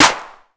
Asap Clap3.wav